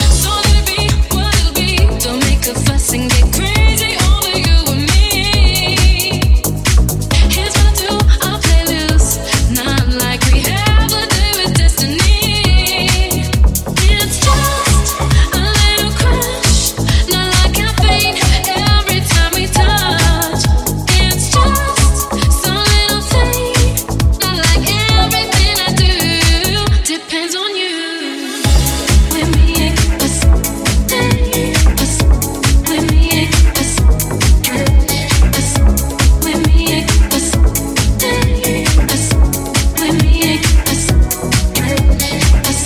Genere: deep house, successi, remix